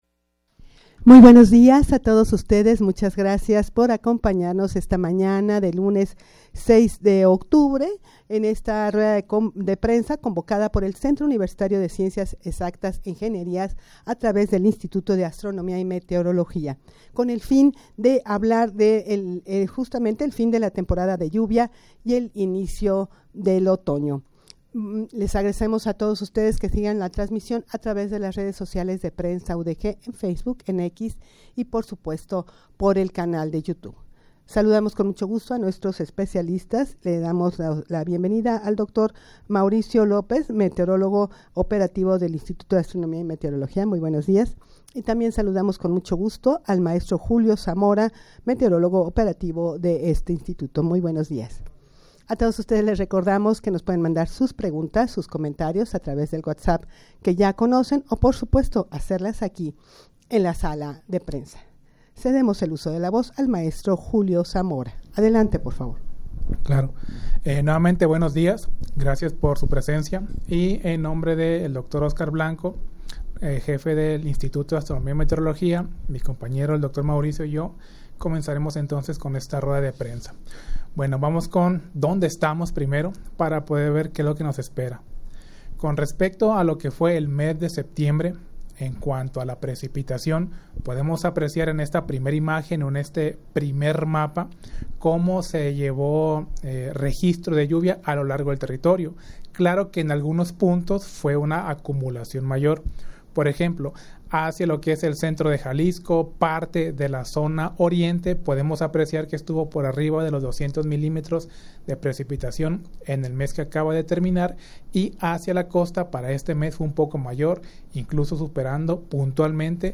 Audio de la Rueda de Prensa
rueda-de-prensa-para-dar-a-conocer-el-pronostico-del-clima-de-octubre.mp3